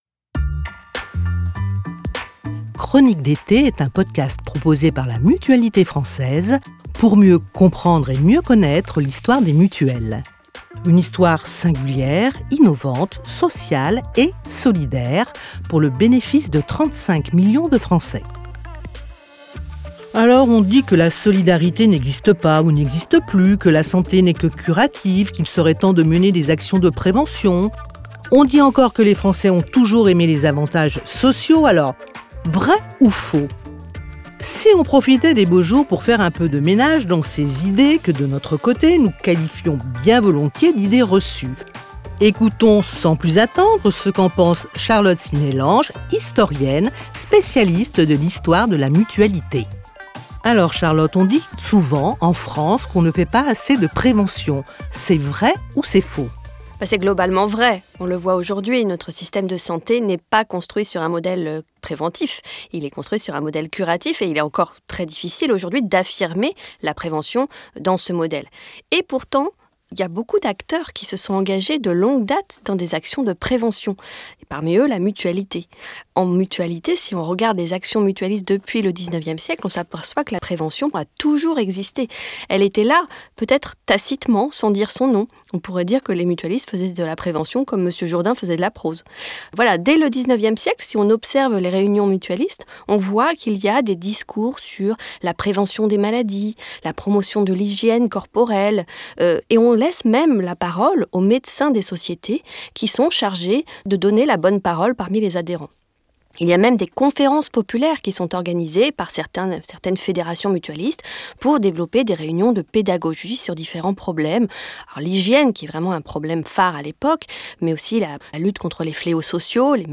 Interview journaliste et conseils